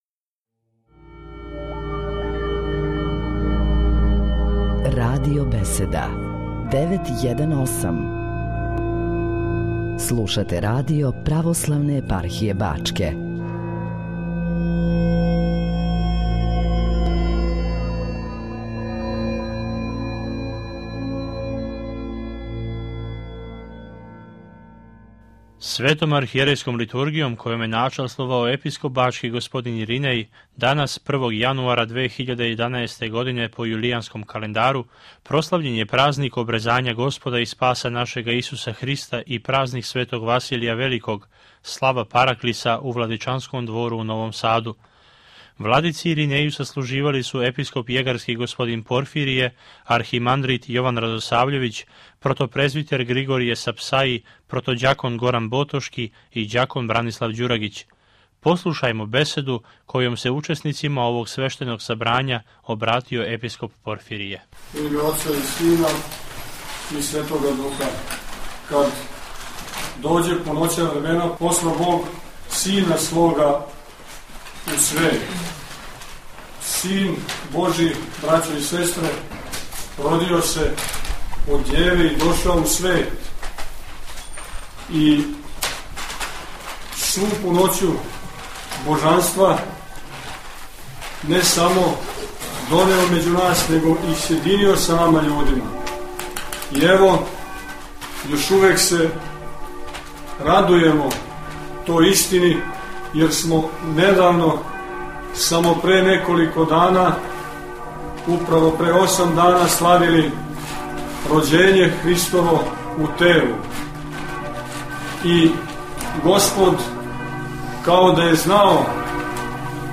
Светом архијерејском Литургијом, којом је началствовао Епископ бачки Господин Иринеј, у петак, 1. јануара 2011. године по јулијанском календару прослављен је празник Обрезања Господа и Спаса нашега Исуса Христа и празник Светог Василија Великог, слава параклиса у Владичанском двору у Новом Саду.